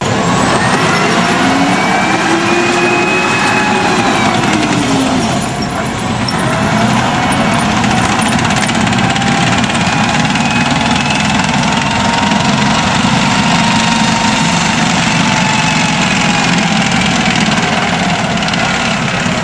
jump1.wav